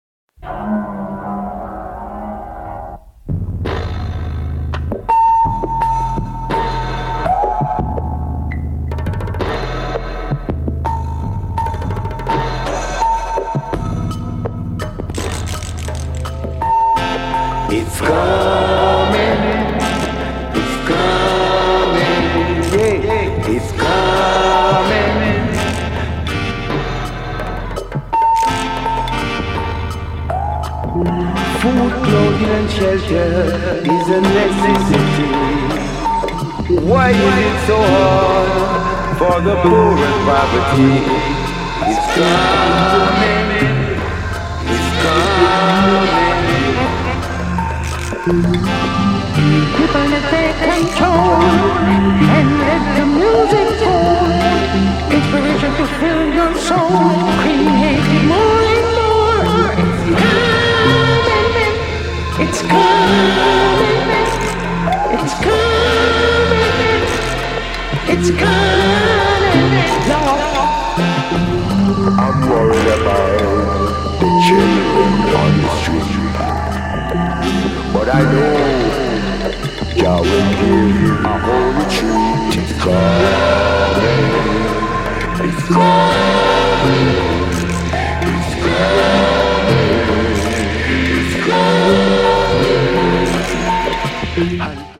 サイケな酩酊感と神々しいような崇高な趣が同居した感じが本当に格好イイですね！